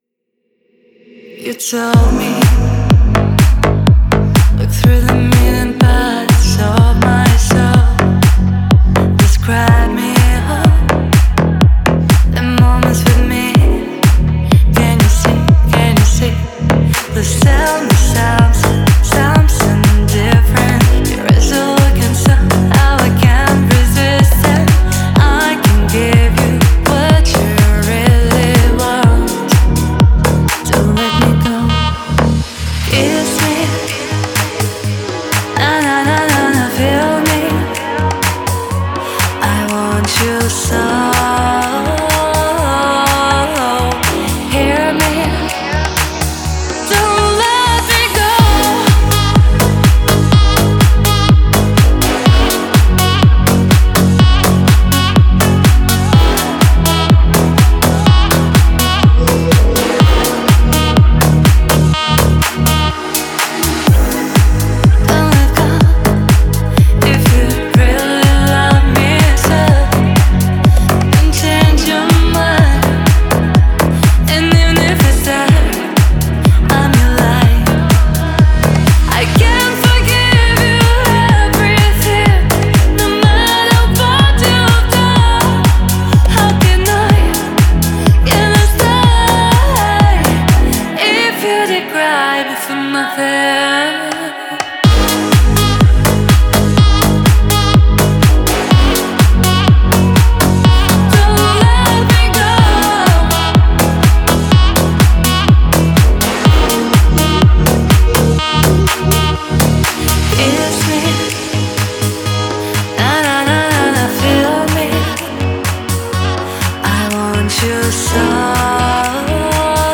эмоциональная поп-баллада